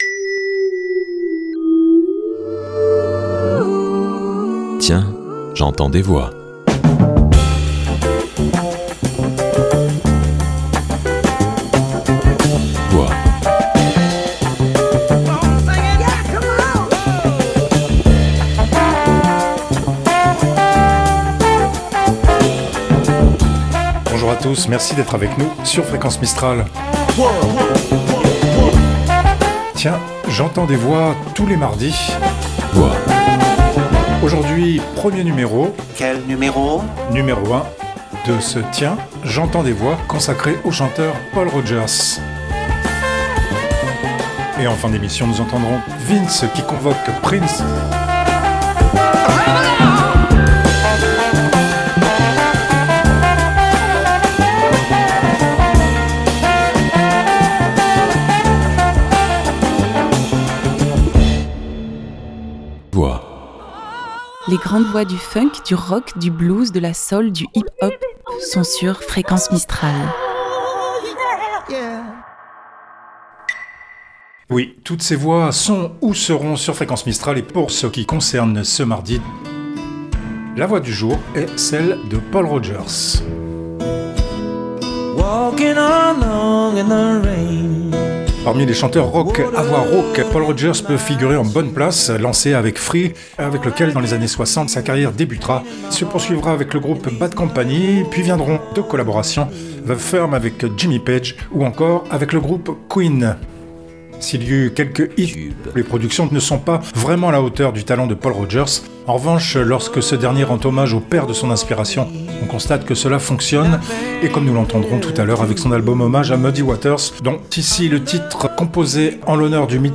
Pack Blues, 3 artistes /Pack Rock, 3 artistes
Générique & jingles, voix additionnelles